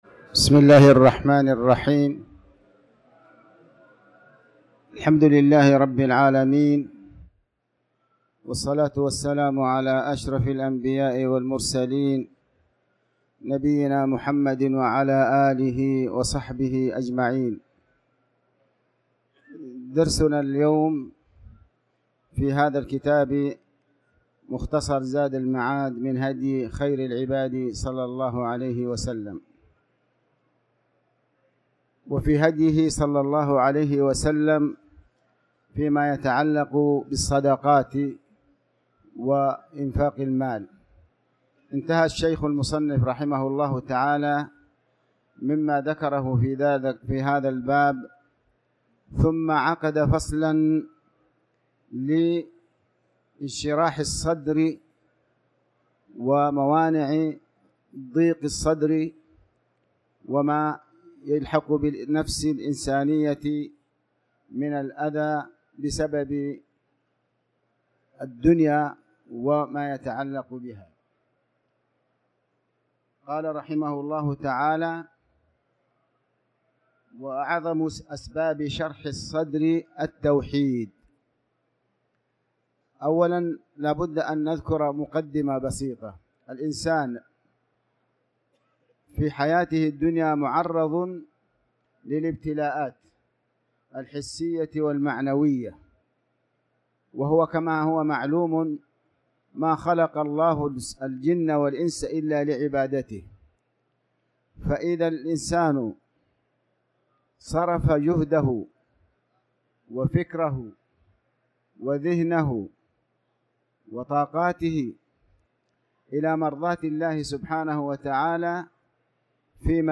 تاريخ النشر ٢٧ رجب ١٤٤٠ هـ المكان: المسجد الحرام الشيخ: علي بن عباس الحكمي علي بن عباس الحكمي صدقة التطوع